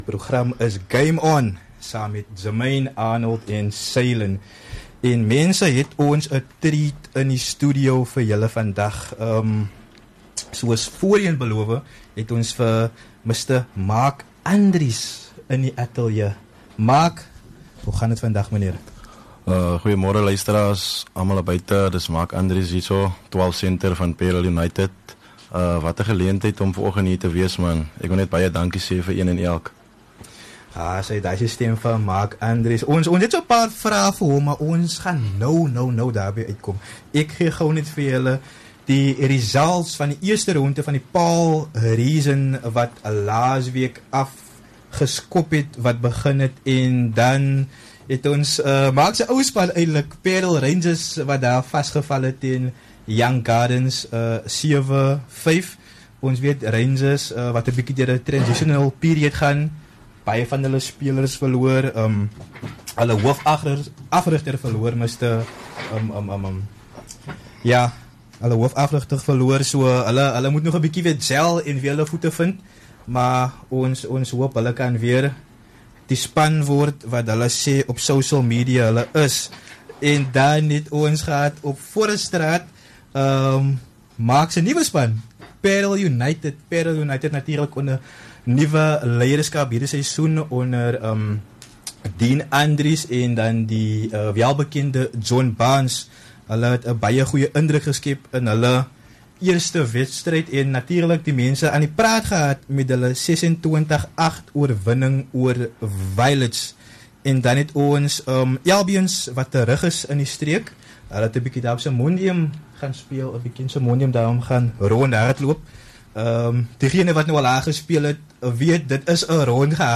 in the studio
In this interview